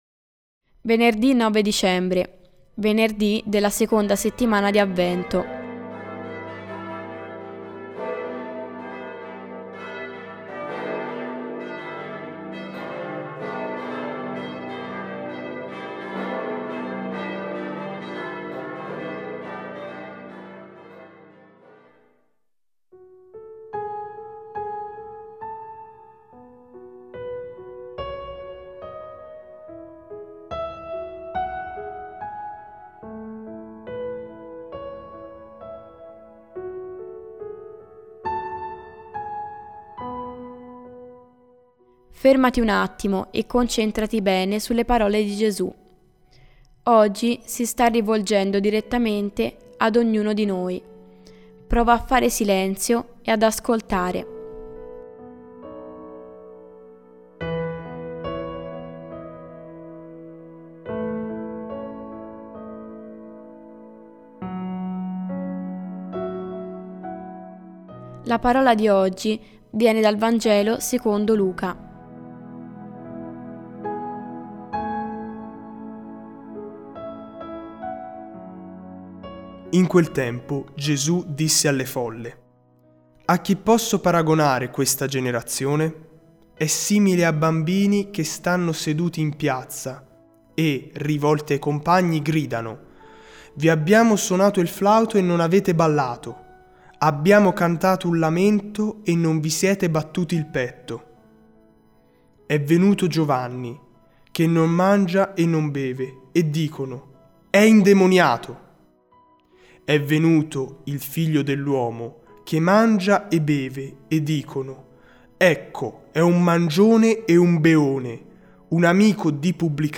Musica: Psalm 84 – Worship Music